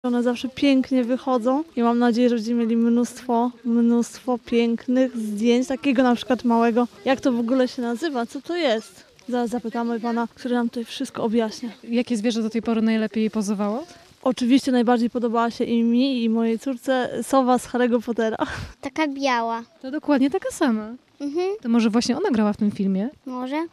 Wycieczka do Kadzidłowa